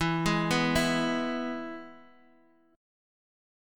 E Chord
Listen to E strummed